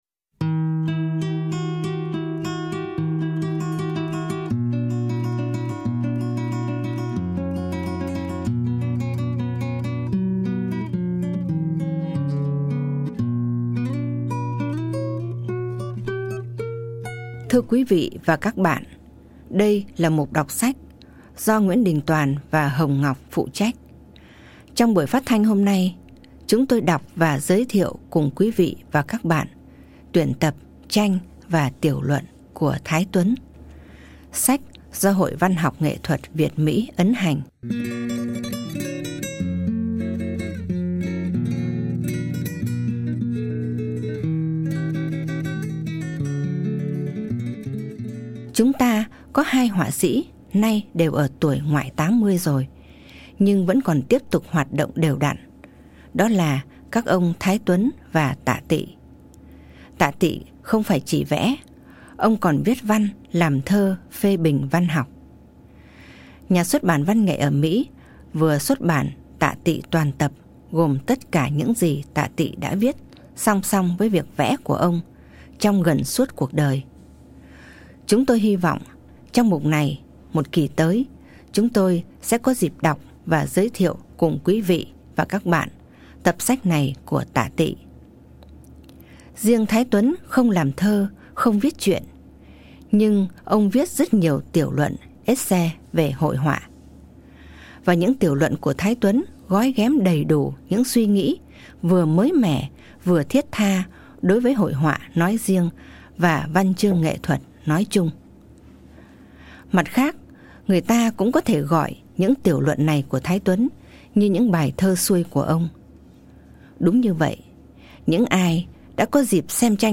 Giọng đọc